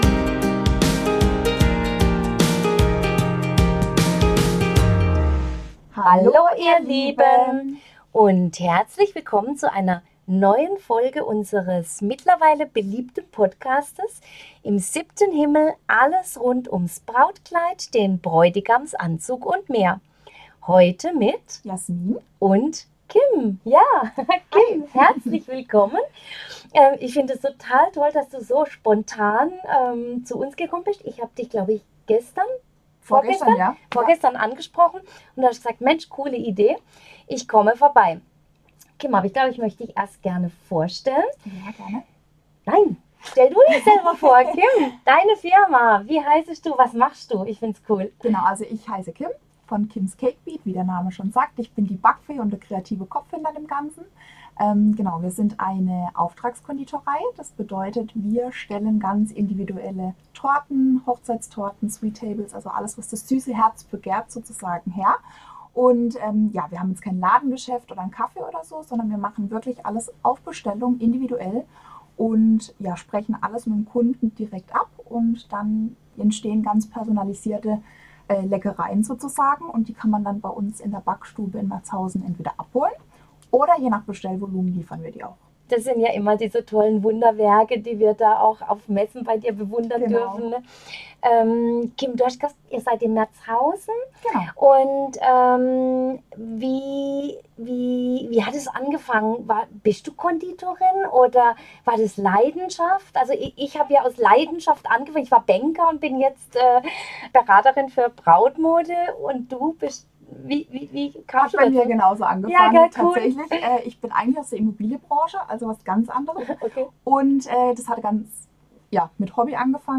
In dieser Folge sprechen wir mit einer erfahrenen Konditorin, die alles über Hochzeitstorten und süße Leckereien weiß.